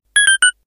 powerUp10.ogg